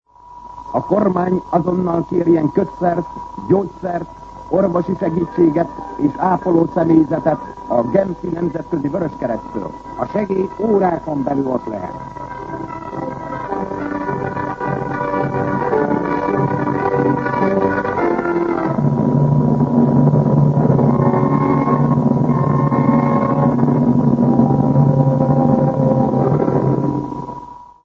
Szignál